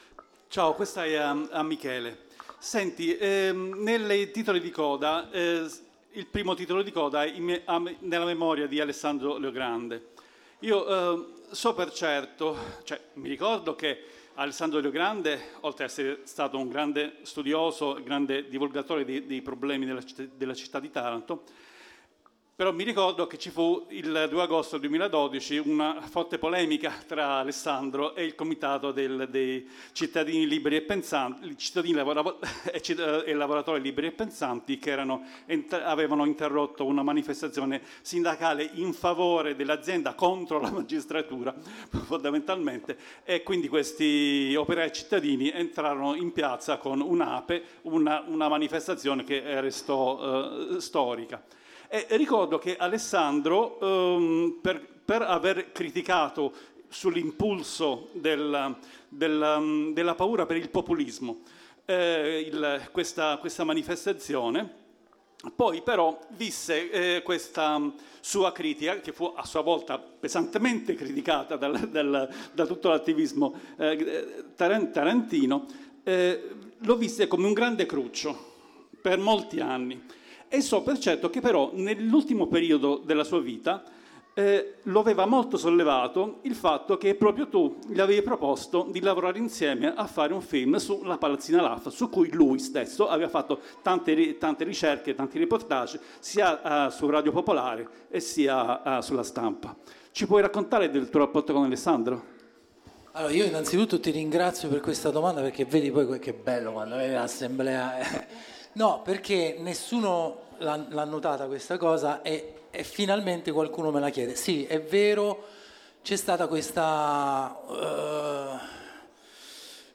Estratto dal dibattito al Centro Sociale Acrobax di Roma, seguito alla proiezione del film "Palazzina LAF"